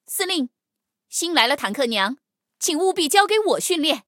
黑豹建造完成提醒语音.OGG